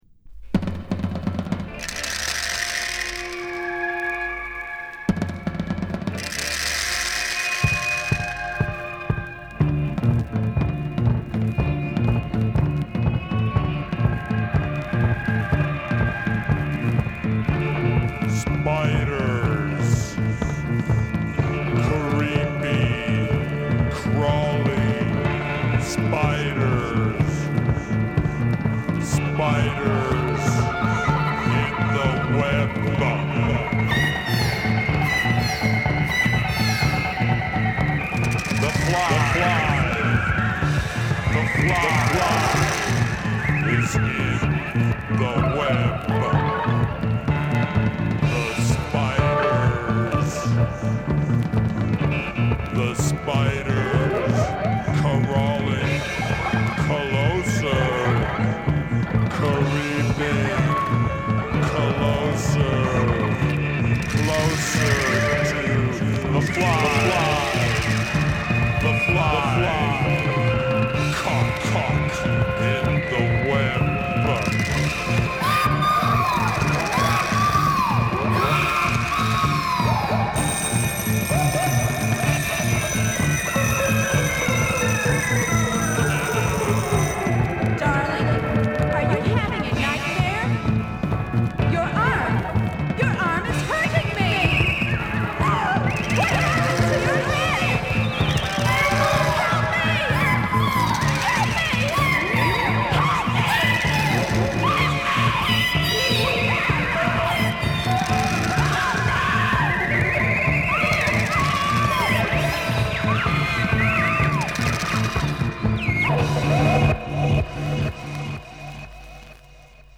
パンク・エッジの効いたアレンジメントが光る